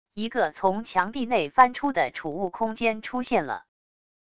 一个从墙壁内翻出的储物空间出现了wav音频